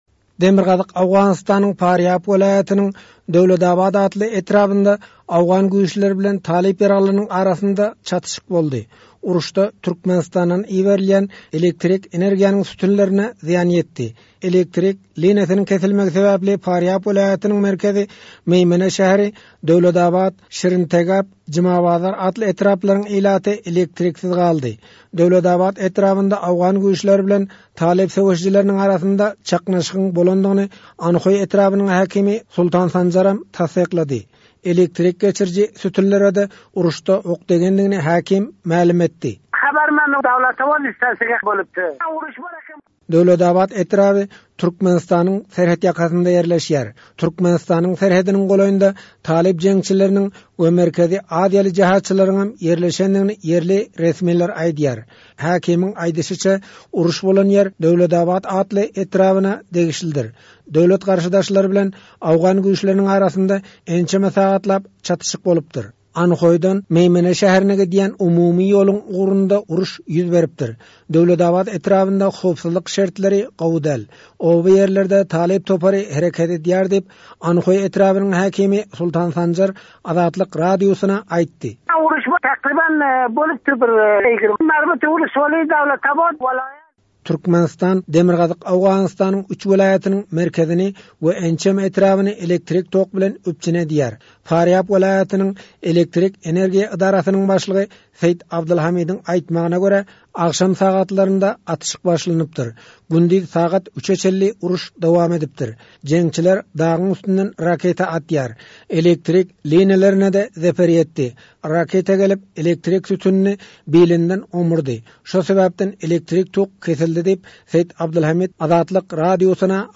by Azatlyk Radiosy